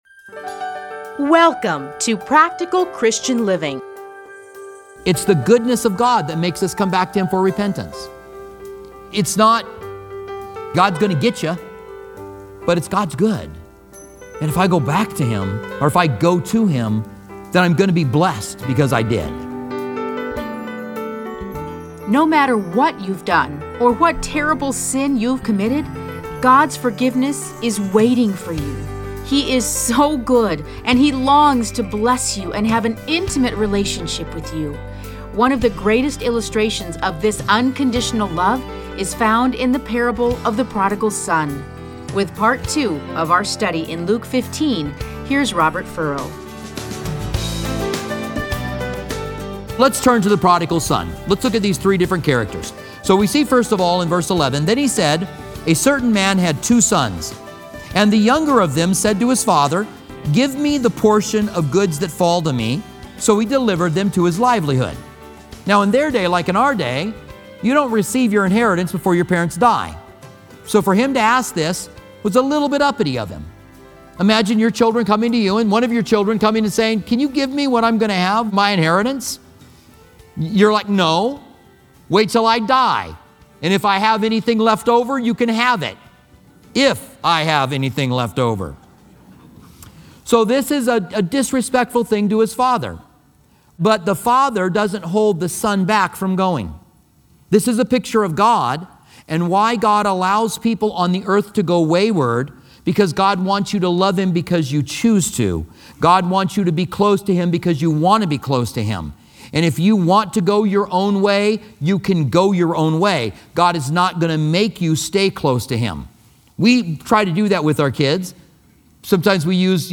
Listen to a teaching from Luke 15:8-32.